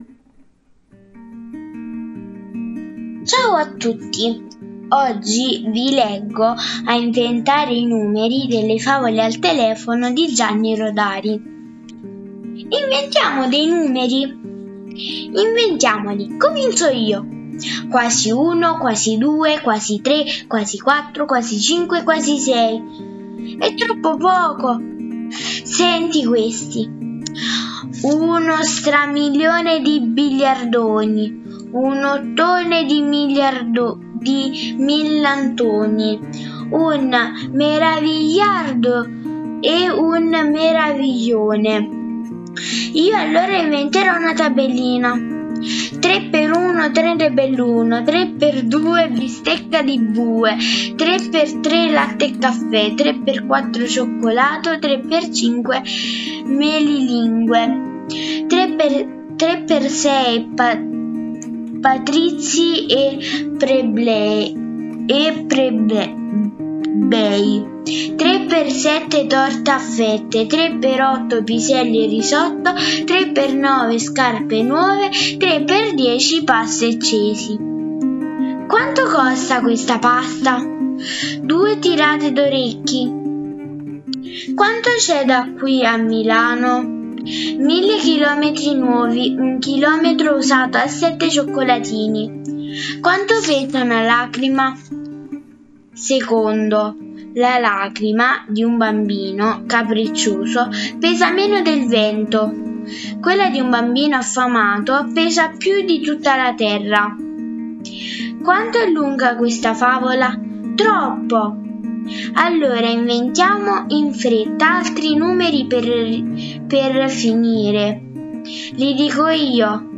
A inventare i numeri | Oggi vi leggo "A inventare i numeri" tratta da libro "Favole al telefono" di Gianni Rodari